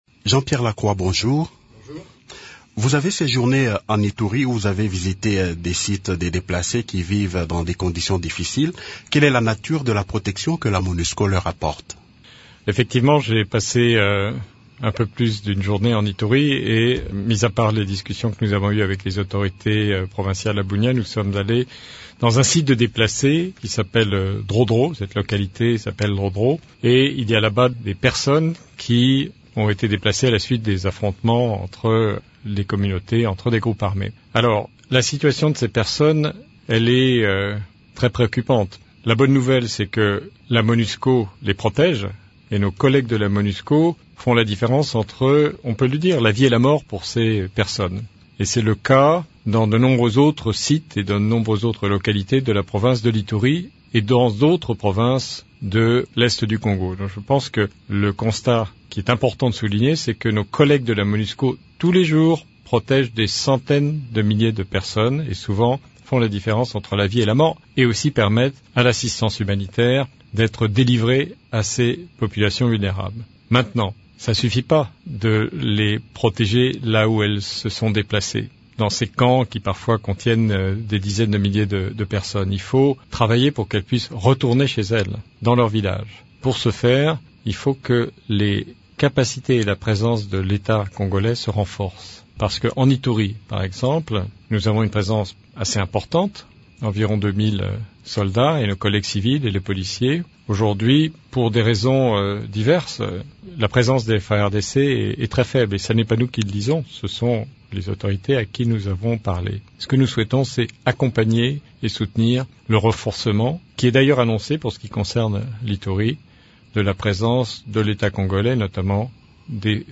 Il a fait cette déclaration lors d’une interview accordée à Radio Okapi.
integralite_interview_jp_lacroix_pad_web.mp3